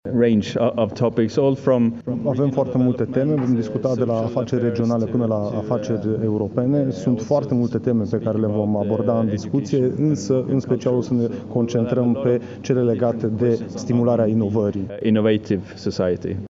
Diplomaţi şi şefi ai regiunilor din Europa se află zilele acestea la Arad, la Plenara de Primăvară a Adunării Regiunilor Europei
Președintele ARE, Magnus Berntsson, a vorbit, cu acest prilej, despre o regiune în care nimeni nu este lăsat în urmă sau tras înapoi, iar asta acest lucru este valabil pentru întreaga Europă.